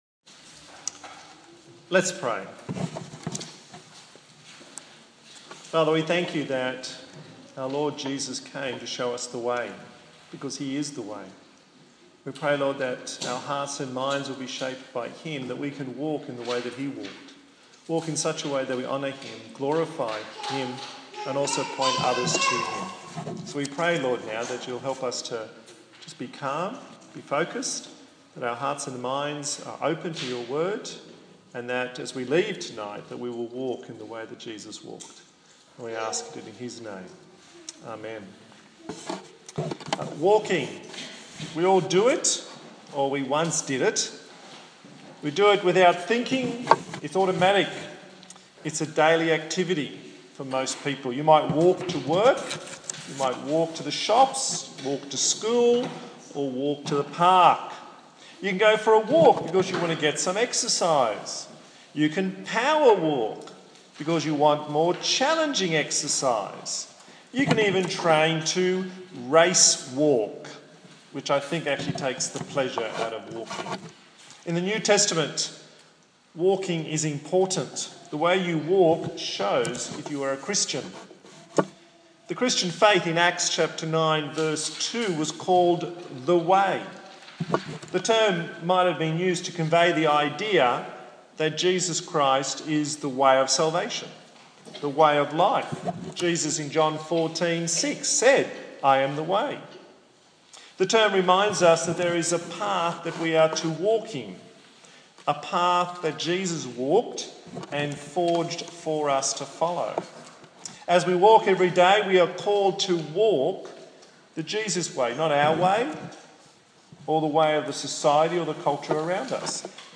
22/11/2015 Walking with a Renewed Mind Preacher